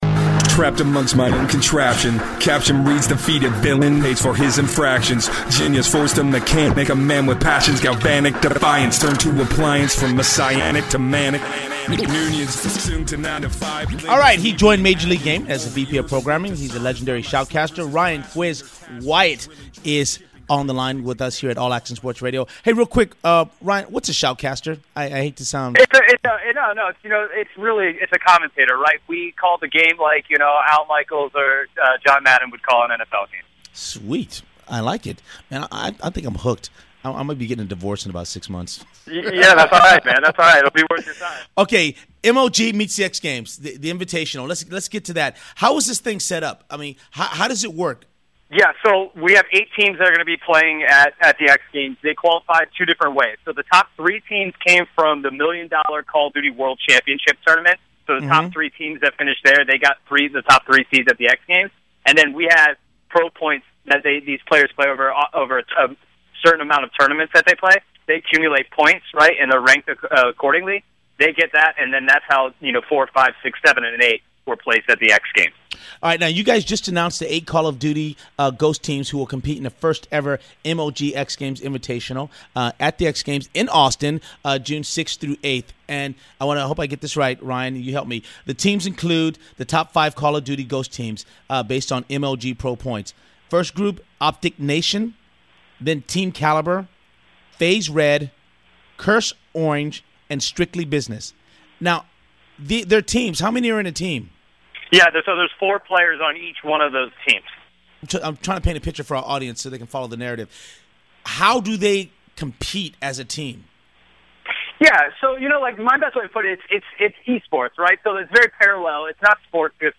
All Action Sports Radio / AASR INTERVIEW